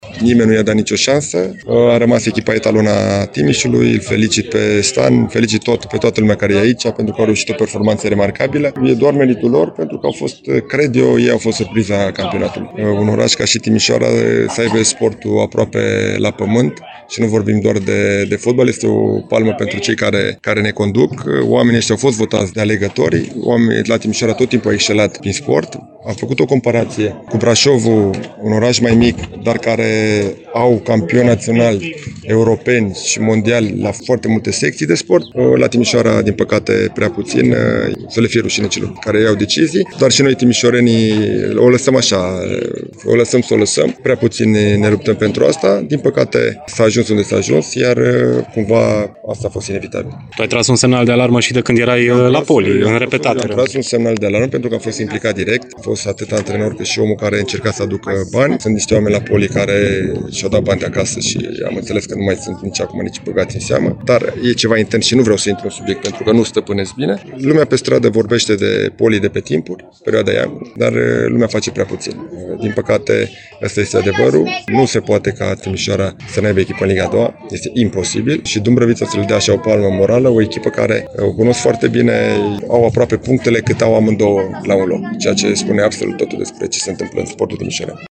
Rușinoasa retrogradare a celor două divizionare secunde timișorene, de pe ultimele locuri ale grupelor de play-out, stârnește reacții dure în rândul foștilor jucători și antrenori de pe Bega. Dan Alexa, acum antrenor la FC Brașov, a avut un discurs dur la adresa celor ce conduc destinele sportului timișorean, la finalul jocului de ieri, câștigat cu 2-1, la Dumbrăvița.